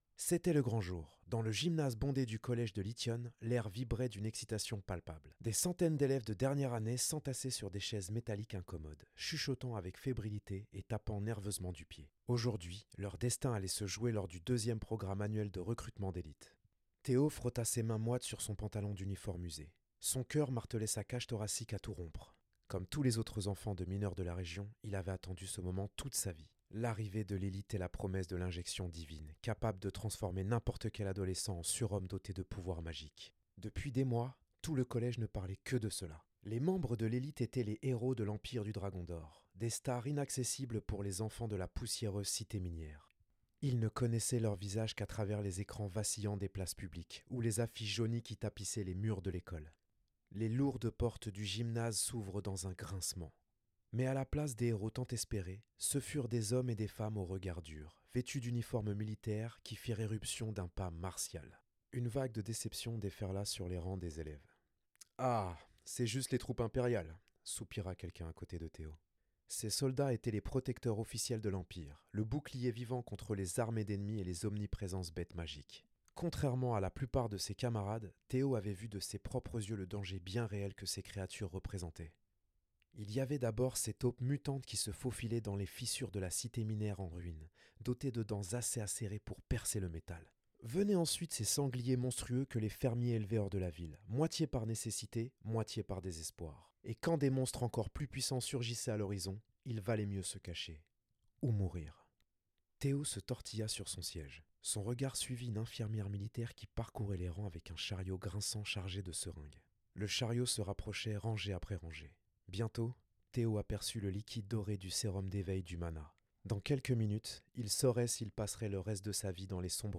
Voix off
audio book